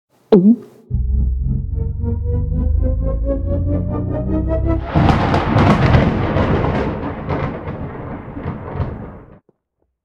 potion.ogg